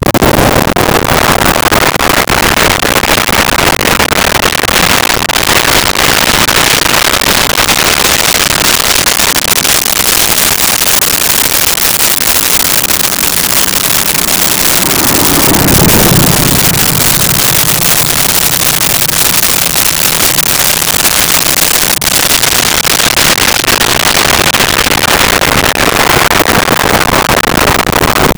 Muscle Car Slow By 40MPH
Muscle Car Slow By 40MPH.wav